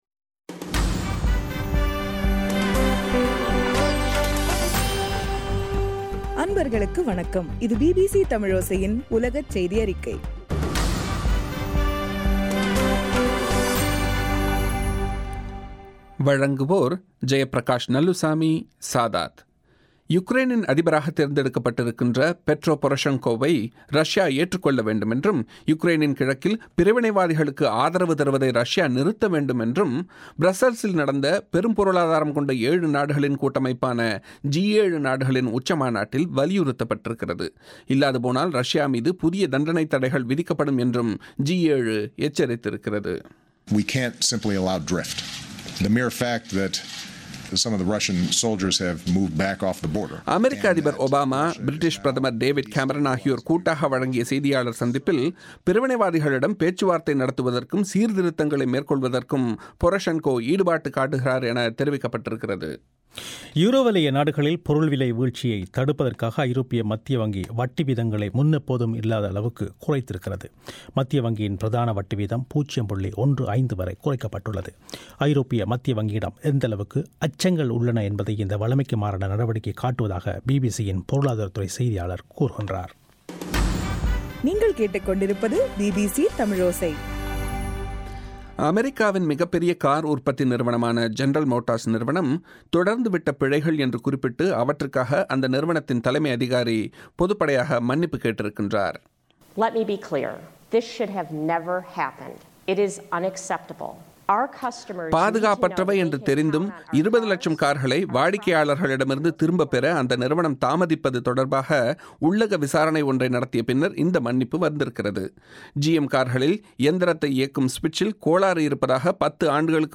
ஜூன் 05 - பிபிசியின் உலகச் செய்திகள்